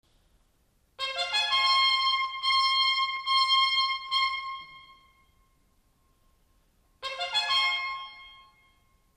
trait d'orchestre 11   Starvinsky pétrouscka solo travail